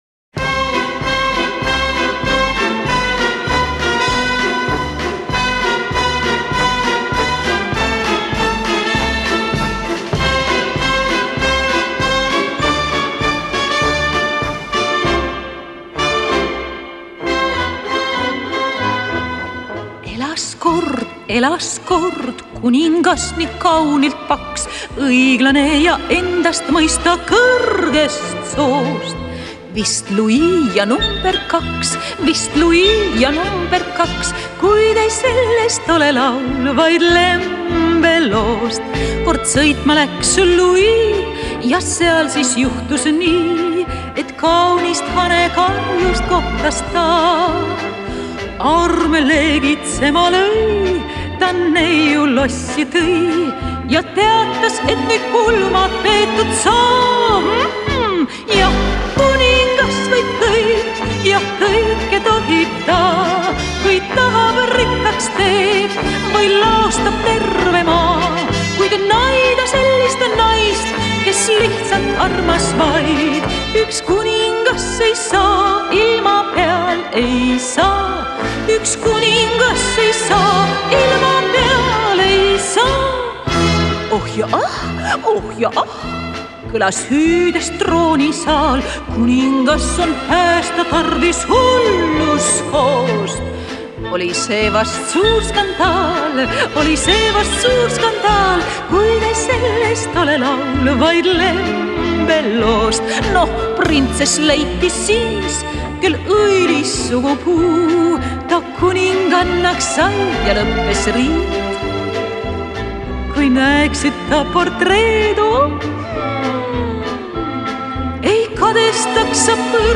меццо-сопрано